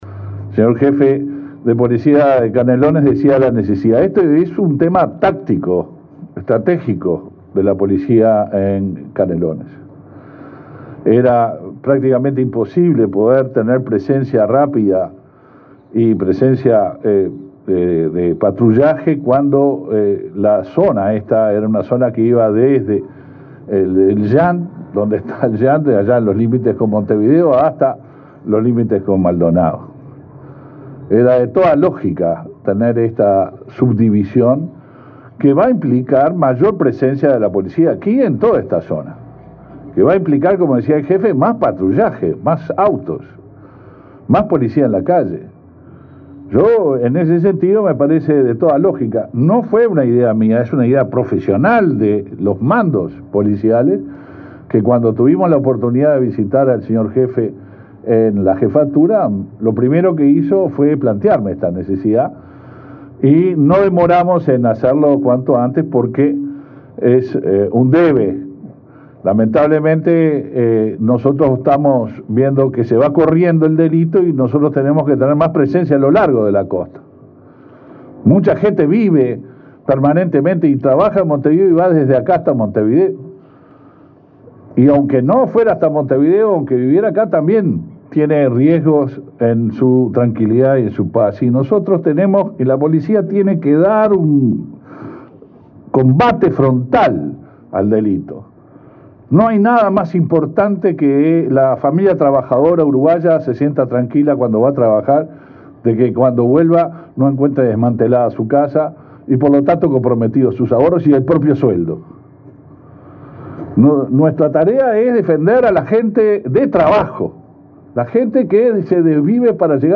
Palabras del ministro del Interior, Luis Alberto Heber
Palabras del ministro del Interior, Luis Alberto Heber 23/08/2021 Compartir Facebook X Copiar enlace WhatsApp LinkedIn El ministro del Interior, Luis Alberto Heber, participó, este lunes 23, de la inauguración de la Base de Zona Operacional V de la Jefatura de Canelones.